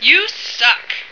flak_m/sounds/female1/int/F1yousuck.ogg at fd5b31b2b29cdd8950cf78f0e8ab036fb75330ca
F1yousuck.ogg